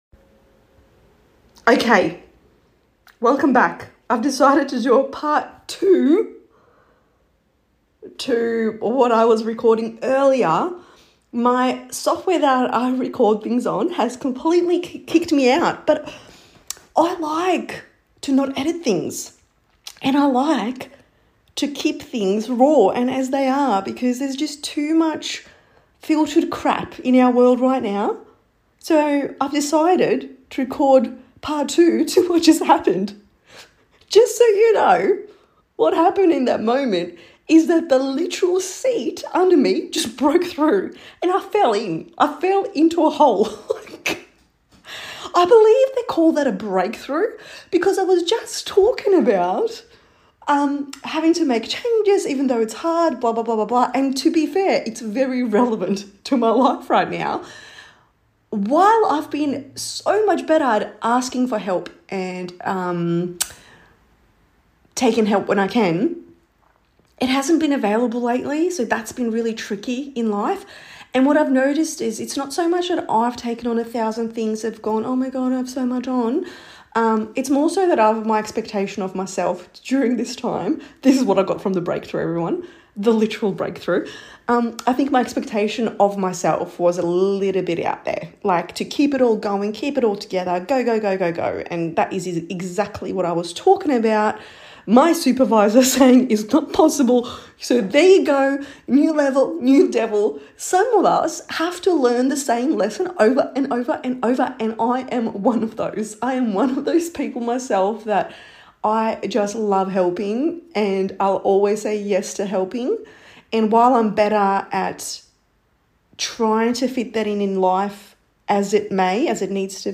In this unedited follow-up to part 1 where I quite literally fell through my seat mid-recording—and somehow, it becomes the perfect metaphor for the topic: breakthrough.